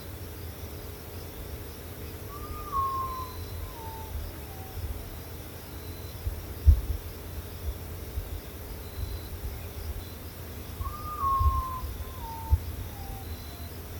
Urutaú Común (Nyctibius griseus)
Nombre en inglés: Common Potoo
Localidad o área protegida: Las Varillas
Condición: Silvestre
Certeza: Observada, Vocalización Grabada